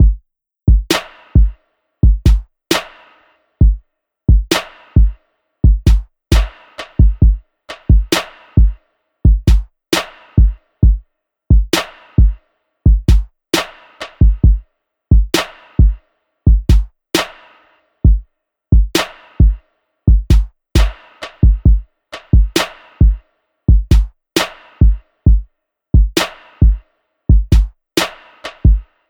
Field Drum Loop.wav